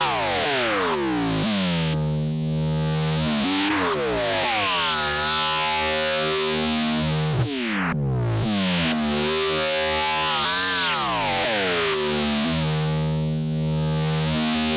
synth.wav